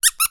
squeak.mp3